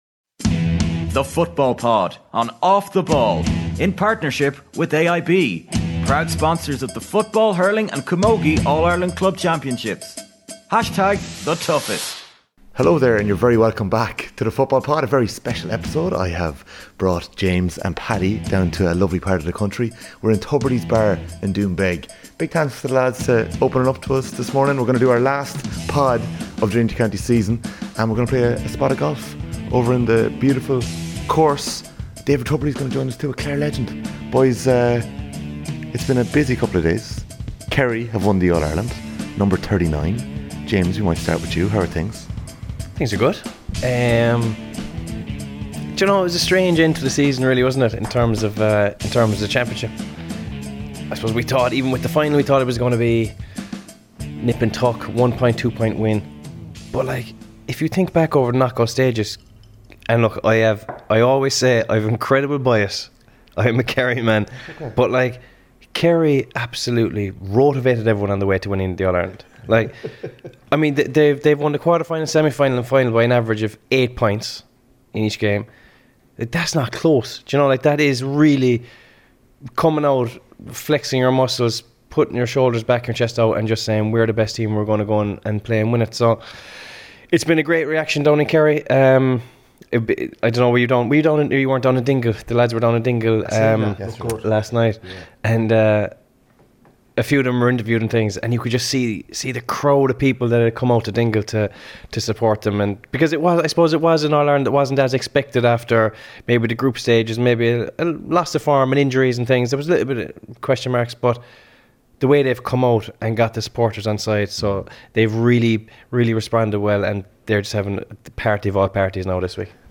edited_podtubridys_fb8849fc_normal.mp3